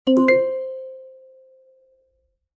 notification_success.BBaju2ao.mp3